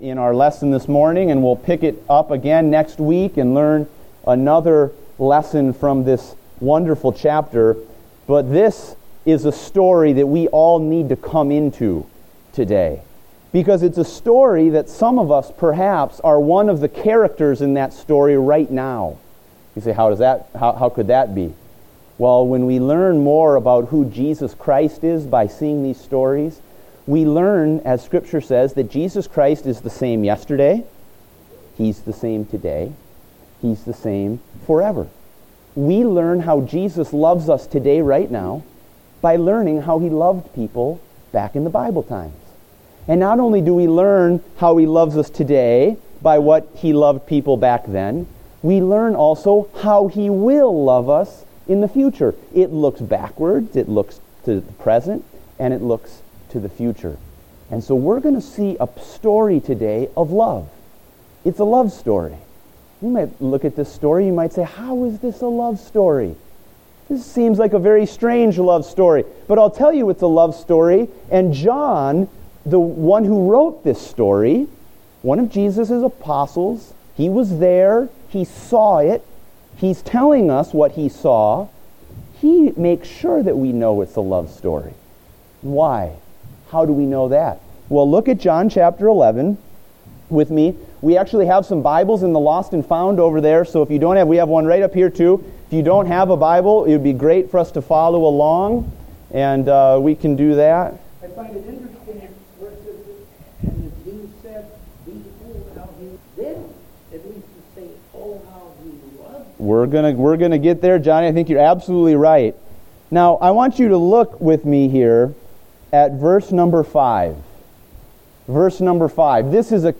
Date: January 31, 2016 (Morning Service)